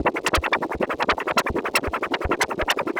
Index of /musicradar/rhythmic-inspiration-samples/80bpm
RI_ArpegiFex_80-01.wav